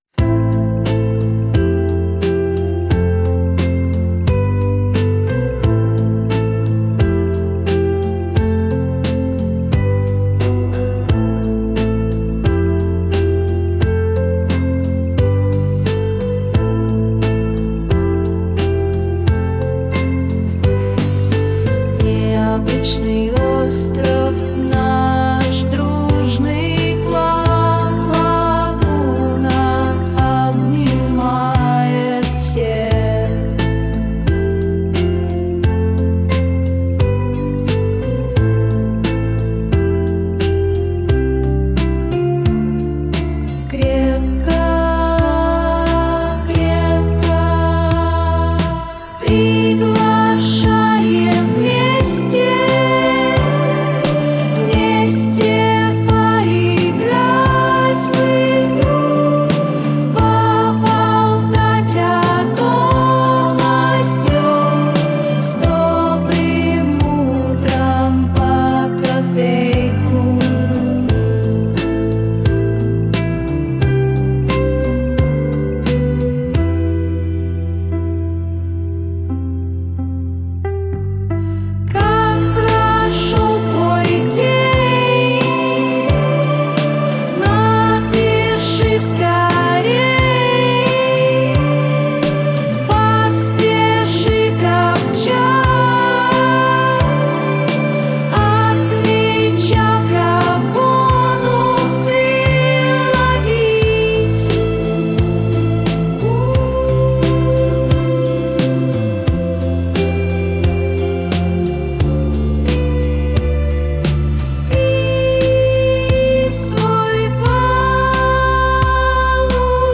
Клан_Лагуна_Мр3 медляк